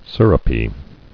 [sir·up·y]